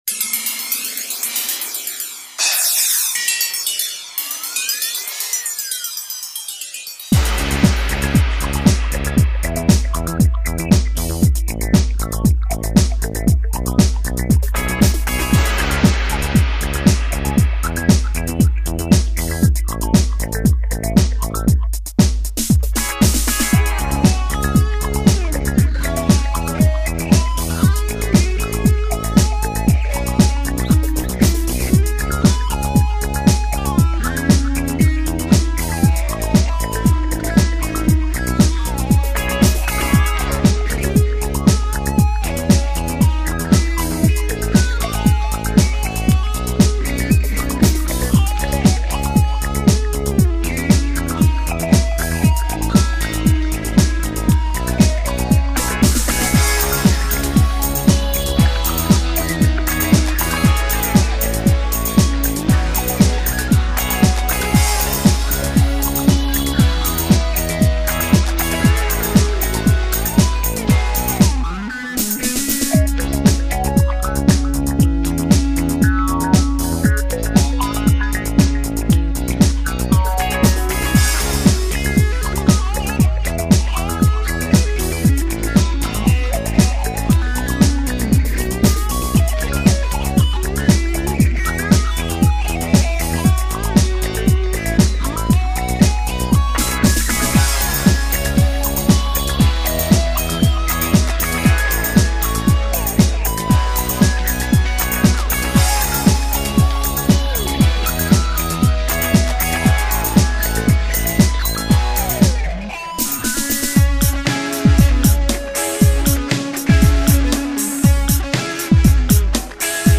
straight synth pop, Jan Hammer style of tune
I love the phaser effect on the guitar.